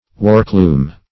Warkloom \Wark"loom\, n. A tool; an implement.